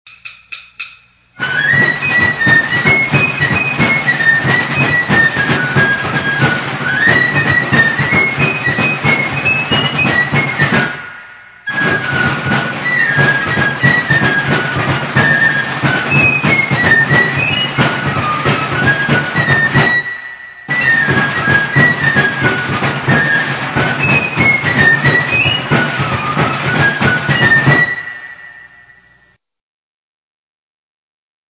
Trommelsignale
(Drummer & Fifer)
Wichtige Trommelsignale und ein paar Rhythmen -